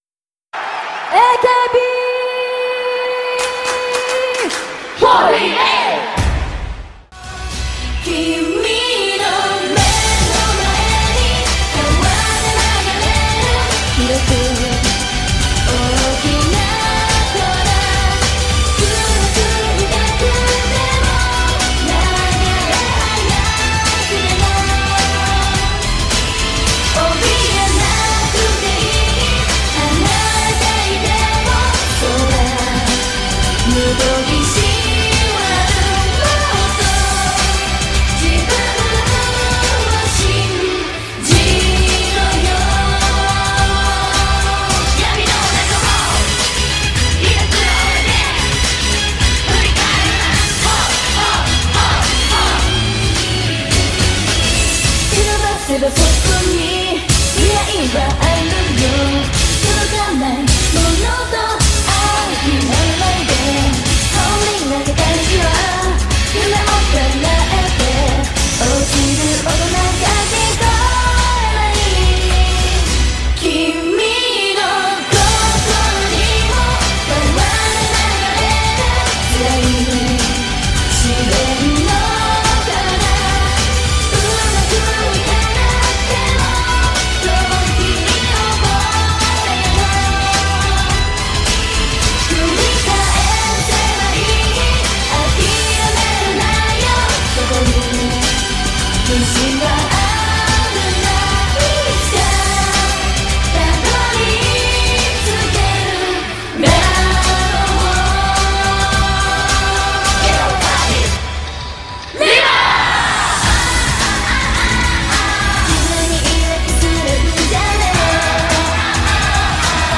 an uplifting and happy blast of positive energy
japanese pop